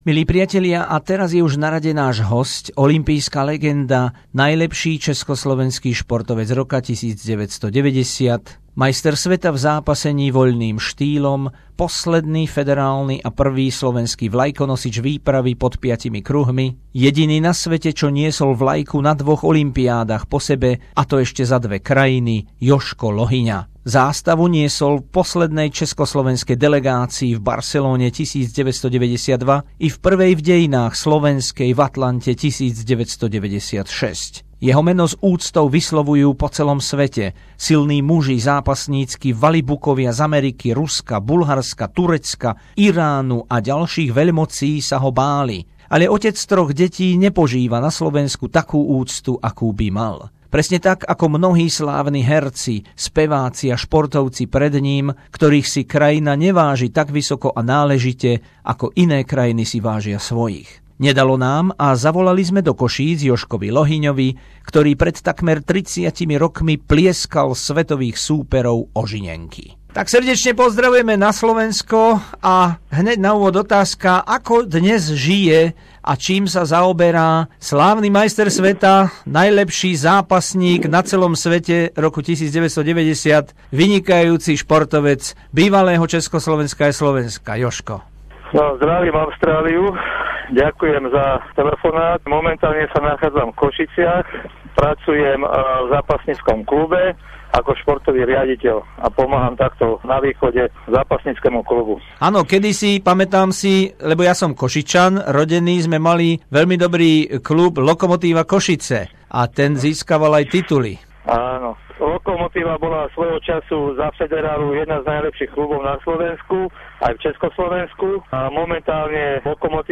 Rozhovor so slávnym slovenským zápasníkom, štvrťstoročie od OH v Barcelone posledného čs. vlajkonosiča Jozefa Lohyňu